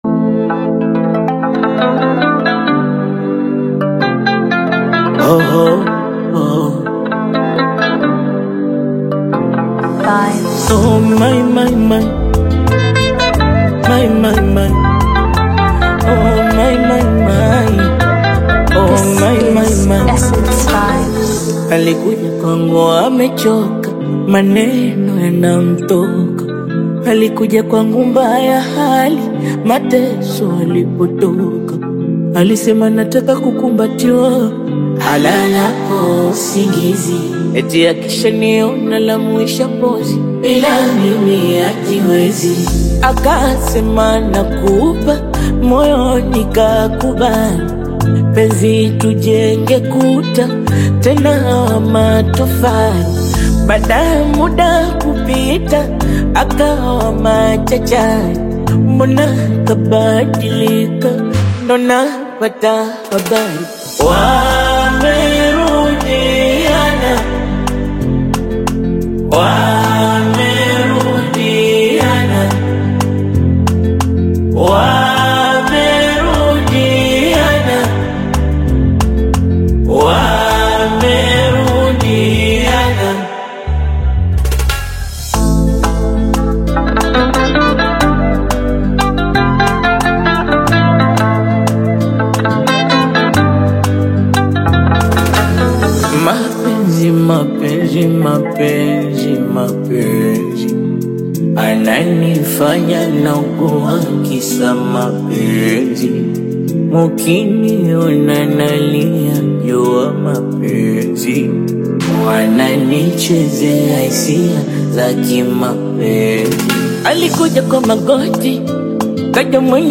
heartfelt Tanzanian Bongo Flava/Afro-Pop single
blends soft vocals with Afro-inspired rhythms